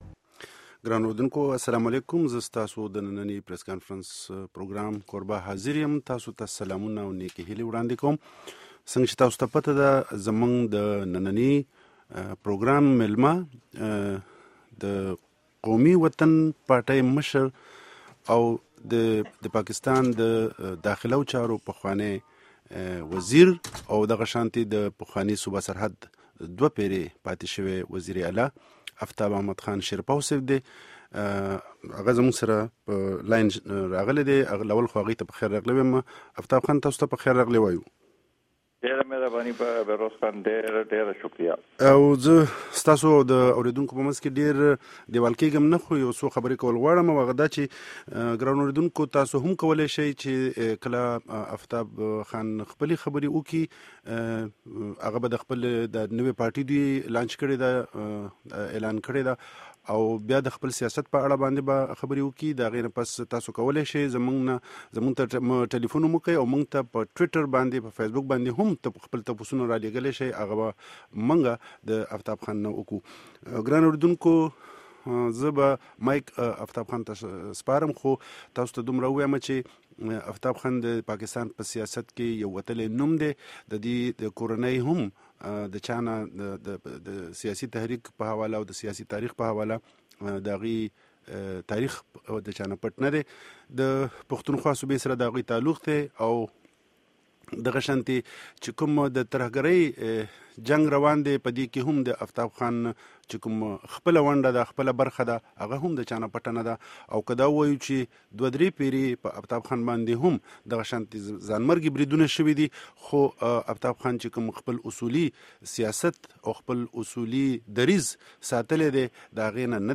Press Conference Aftab Ahmed khan sherpaw